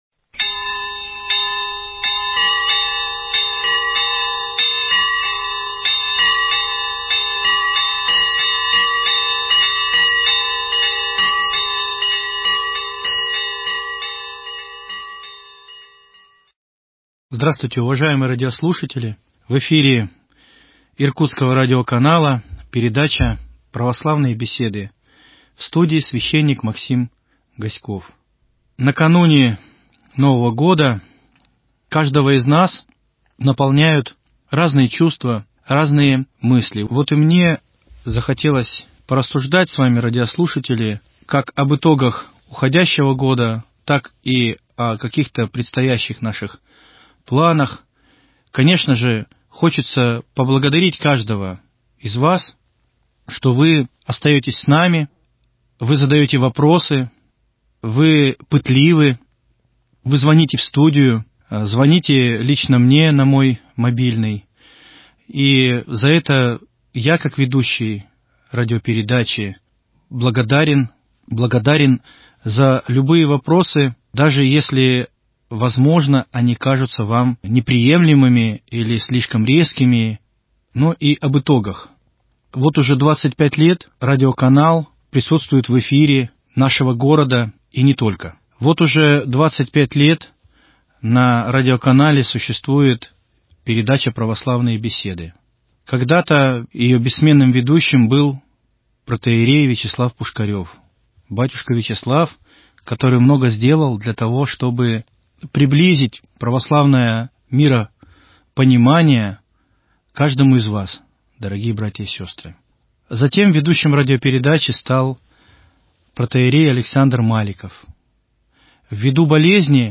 Запись сделана накануне празднования новолетия.